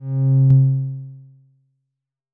switch_gravity.wav